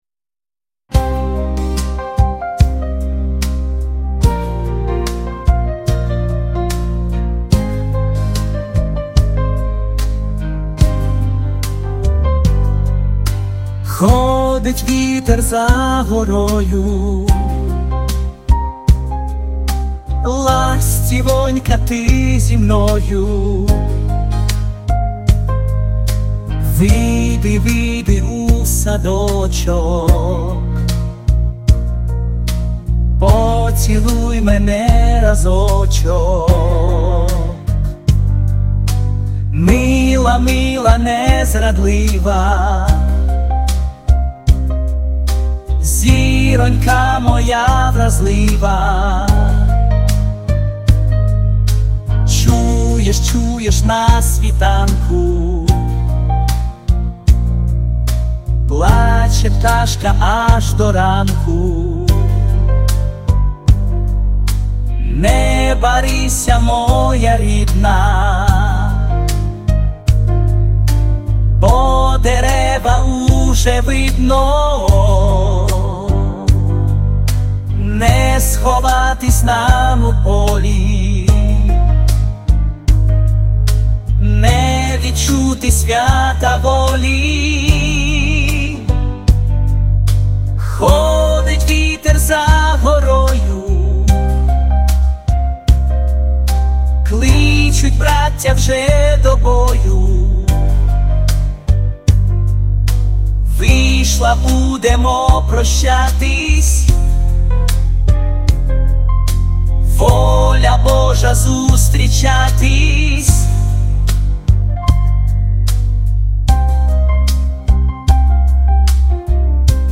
(Пiсня)